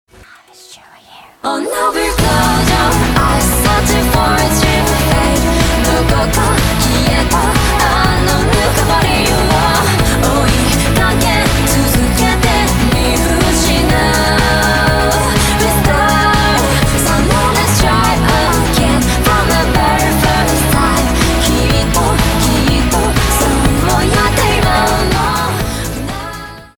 • Качество: 128, Stereo
поп
anime